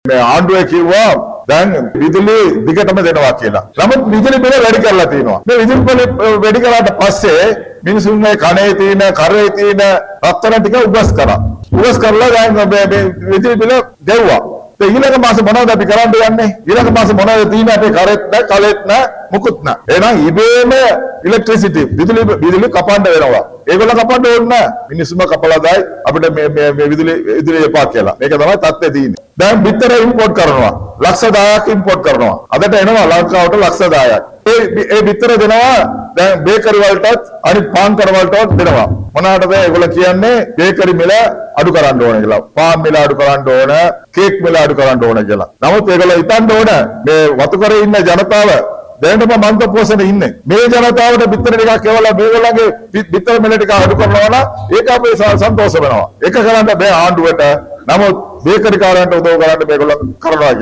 හැටන් ප්‍රදේශයේ පවතී ජන හමුවකදී පර්ලිම්නේතු මන්ත්‍රී වි රාධාක්‍රිෂ්ණන් මහතා මේ බව ප්‍රකාශ කළා.